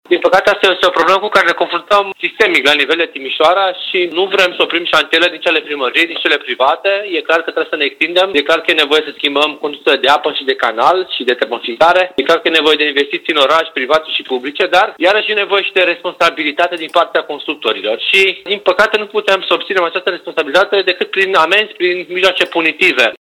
Viceprimarul Ruben Lațcău spune că problema mizeriei provenite de pe șantiere poate fi combătută doar prin intervențiile ferme ale Poliției Locale.